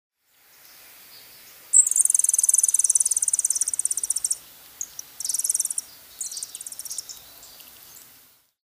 Dark-eyed Junco
They also have a very nice little song, being known within the birding set as an excellent bird through which to study ‘bird language’.